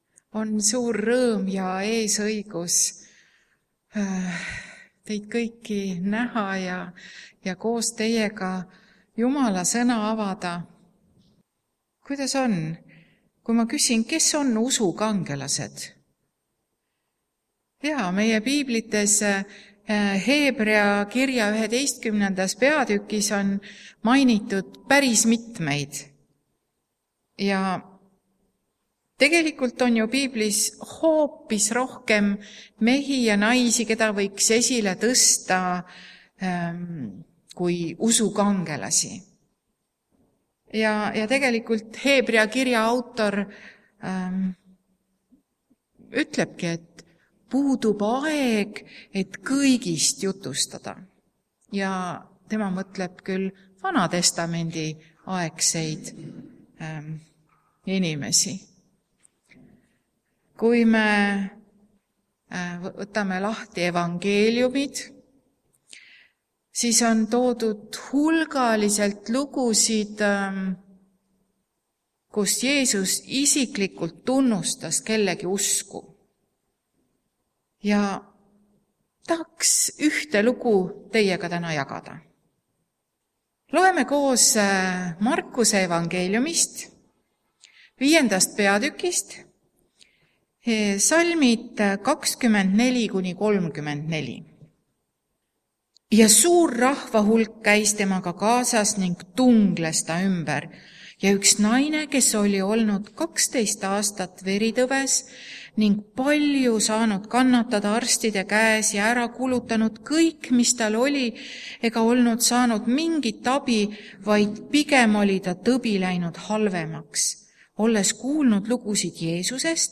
Jutlused
Lauluhetkeks vanem laul aastast 1976